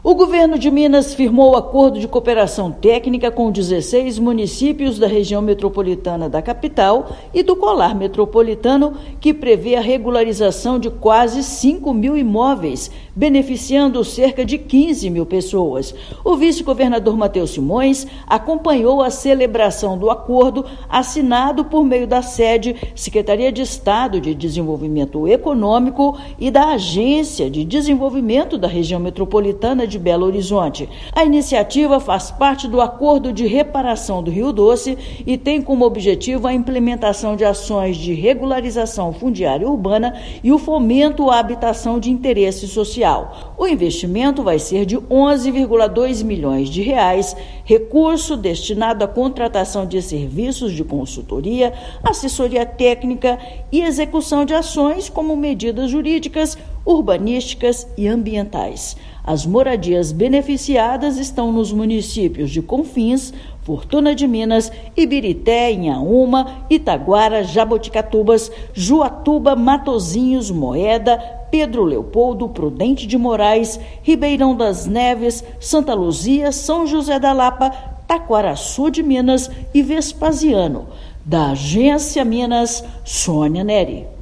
Iniciativa contará com investimentos de R$ 11,2 milhões, oriundos do Acordo de Reparação do Rio Doce, e beneficiará cerca de 4.870 famílias. Ouça matéria de rádio.